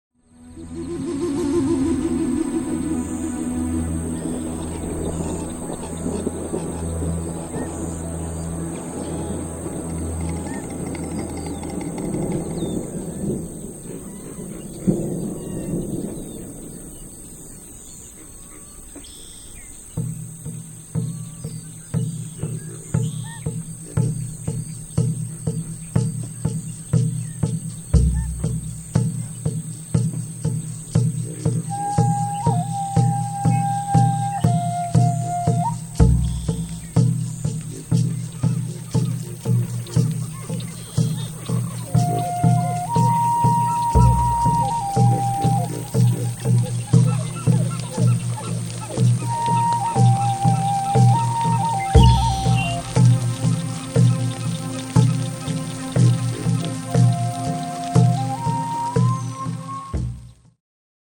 Original Soundtrack Music and Nature Sounds